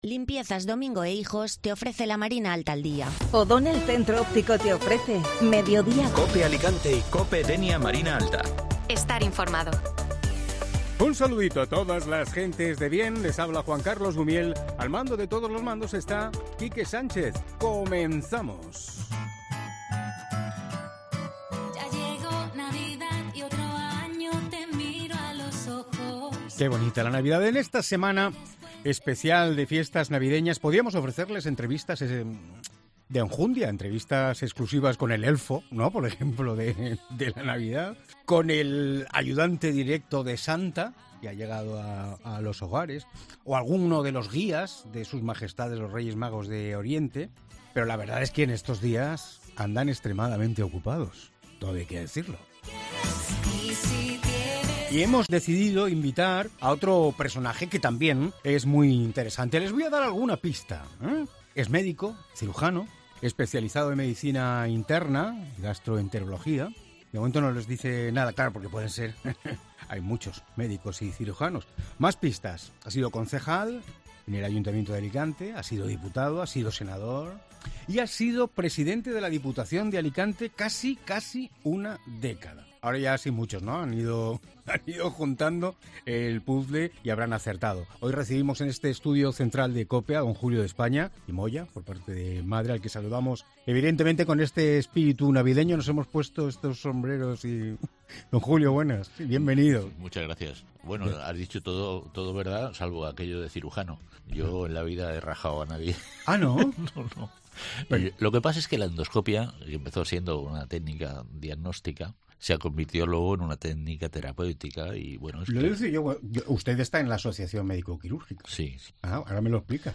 AUDIO: Escucha la entrevista en Mediodía COPE Alicante, a Julio de España, ex-presidente de la Diputación Provincial de Alicante